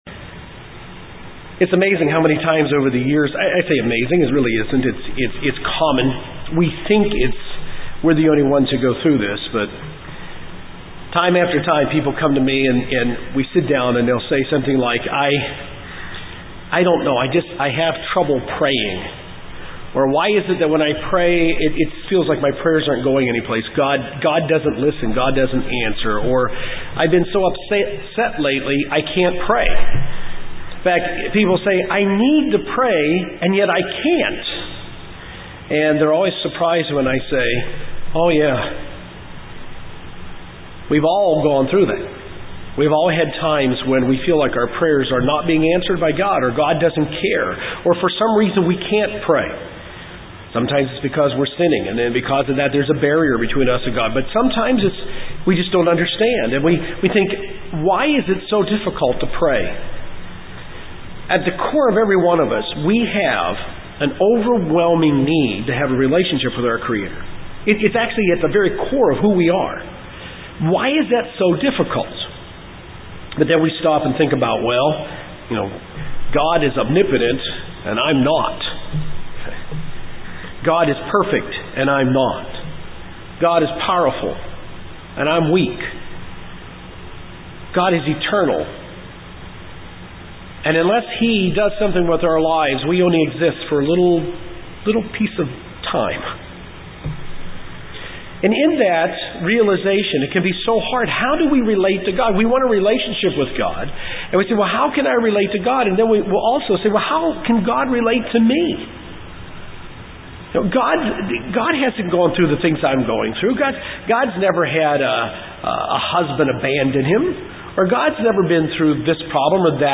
There are reasons why we can't pray. This sermon will cover seven basic principles about prayer that will help us in our prayers to God.